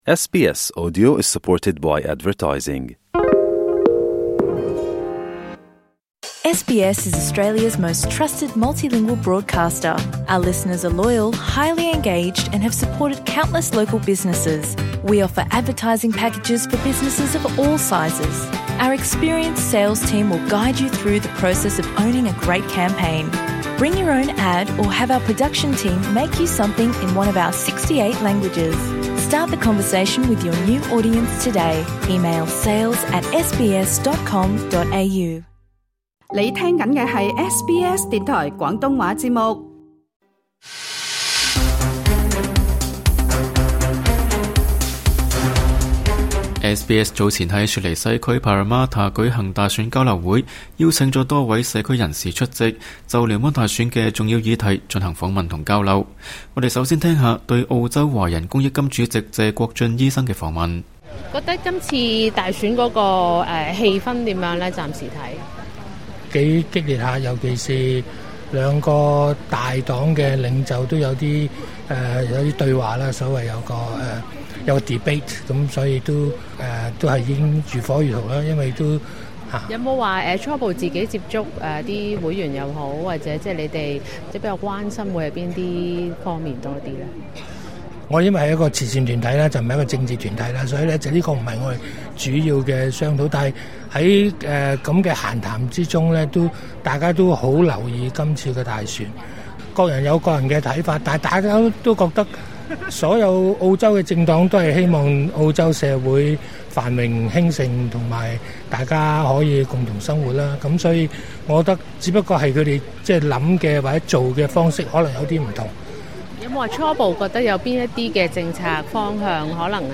聯邦大選在即，SBS早前在雪梨西區Parramatta舉行大選交流會，邀請了多位華人社區代表出席，就聯邦大選的重要議題進行訪問和交流。